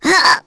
Nia-Vox_Damage_01.wav